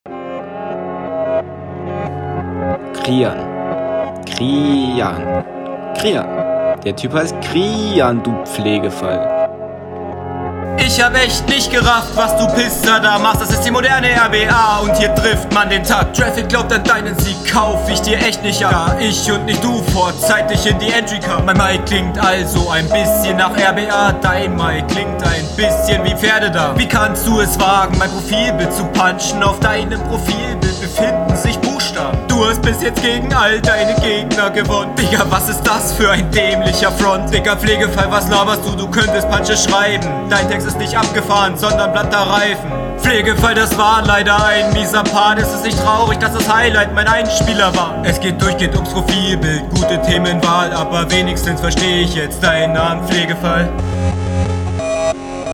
Wieso schreist du denn so haha der Beat ist doch so gechillt.
gute delivery, technik stabil, flowlich simpel doch onbeat. punchlinetechnisch: meh. du hast 1-2 lines gut …